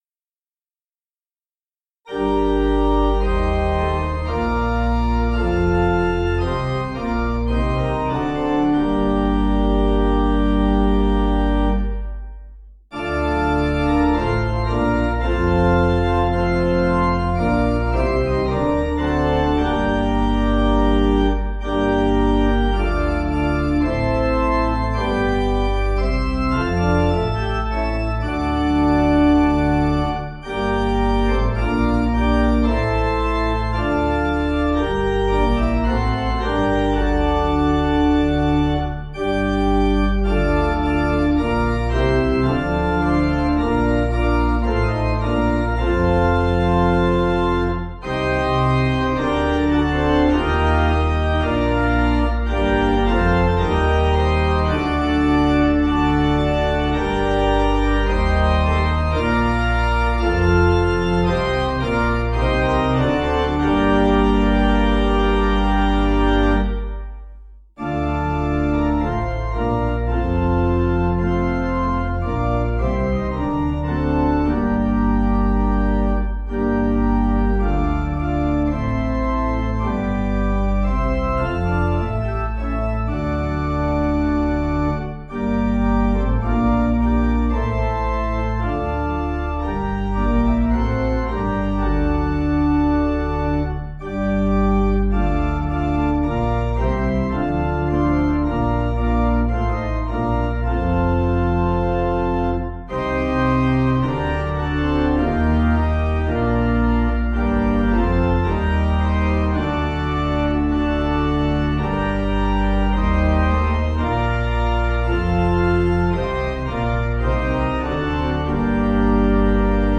Organ
(CM)   5/Gm